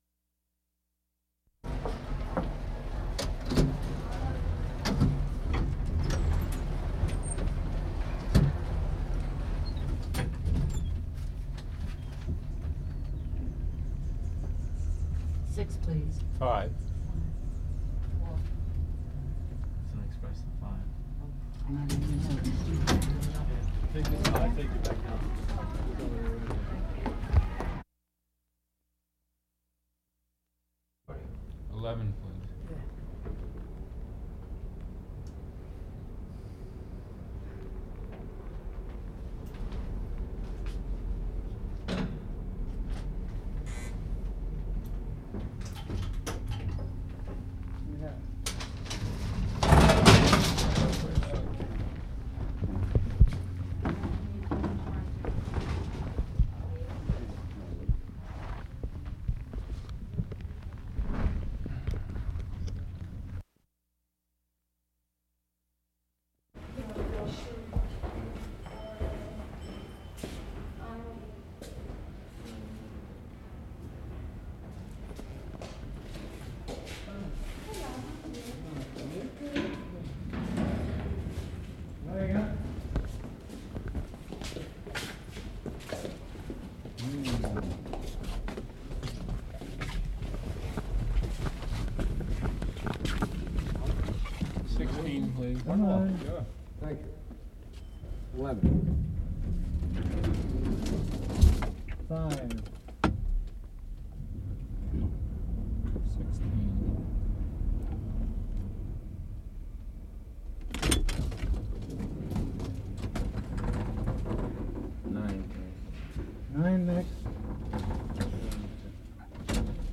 Elevator operators
Contents: 1. Department store elevator operator -- 2. Elevator door opening -- 3. Elevator ride -- 4. Addison Hall (W. 57th St.) elevator -- 5. Ride down elevator (12 E. 41st St.) -- 6. Ride up elevator (12 E. 41st St.) -- 7. Ride in Doctor's Hospital to 11th floor -- 8. Ride up "Best & Co." to 5th floor.